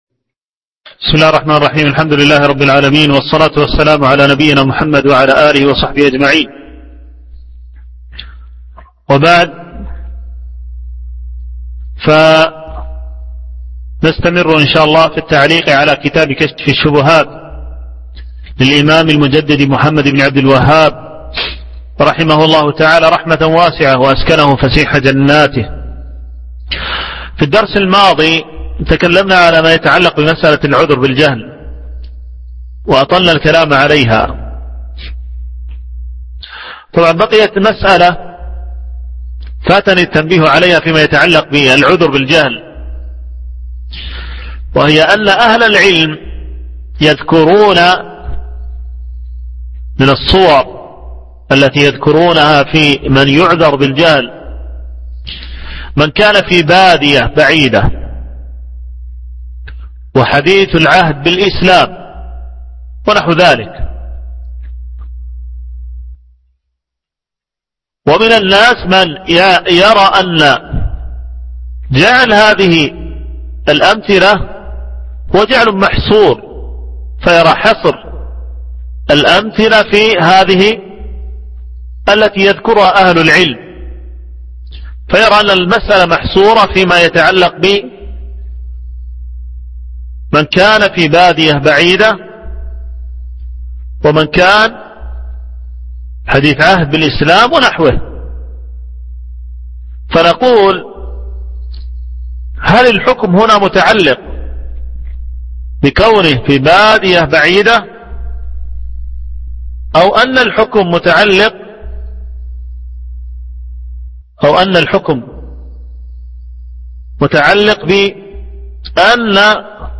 شرح كشف الشبهات - الدرس الخامس